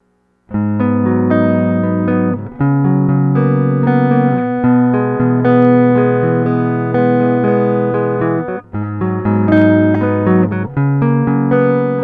Daisy Seed + Terrarium Rev5 -> Rev7 terrible SNR
When I went to test the pedal with my new seed, the same software was running with an incredible amount of noise. Here are two examples of daisy seed rev5 vs rev7 flashed with input routed to output.